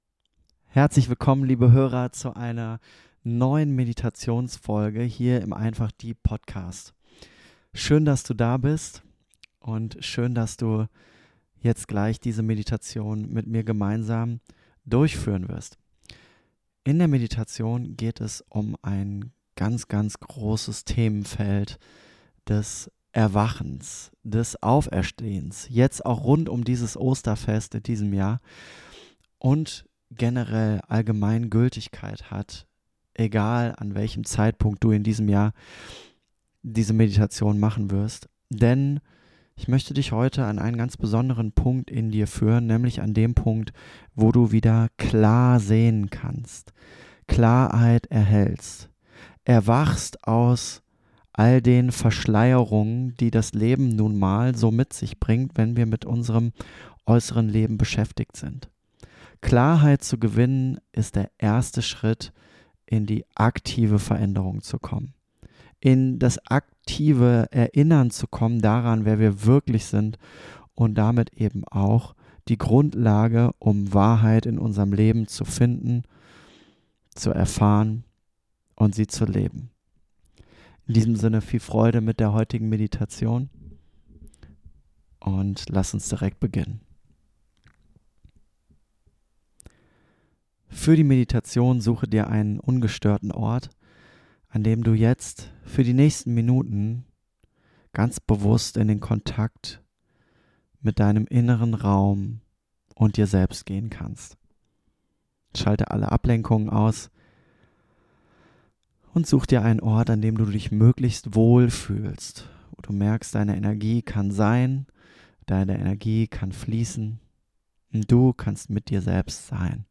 Erwachen & innere Klarheit – Eine Meditation für Wahrheit, Herzöffnung und Neubeginn ~ Einfach DEEP Podcast